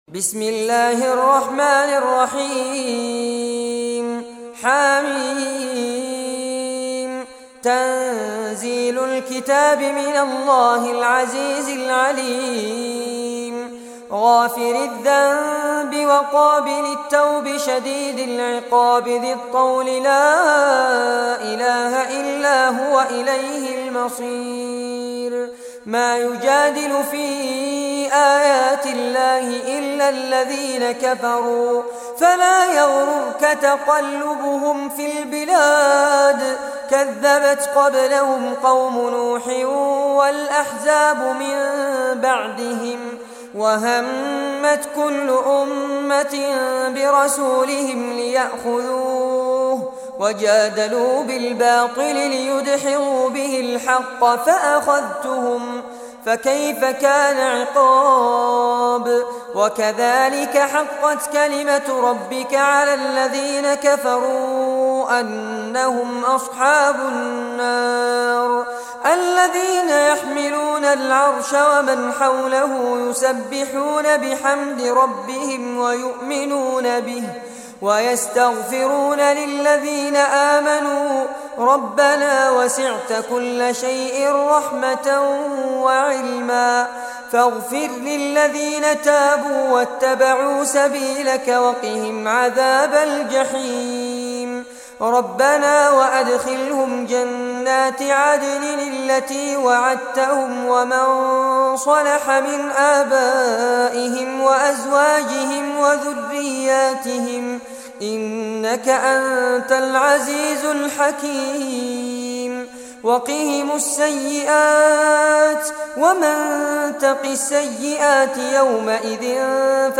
Surah Ghafir, listen or play online mp3 tilawat / recitation in Arabic in the beautiful voice of Sheikh Fares Abbad.